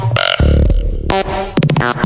扑通声
描述：扑通声
标签： 声音 扑通 FX 音效设计
声道立体声